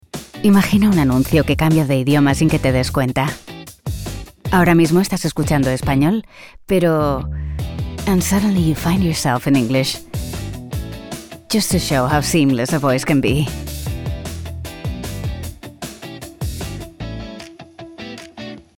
Versatile, Elegant, Sincere, Warm tones. 30-40.
Conversational, Bright, Upbeat, Natural